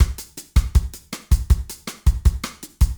Drum Loops
Bossa 4
Straight / 160 / 2 mes
BOSSA1 - 160.mp3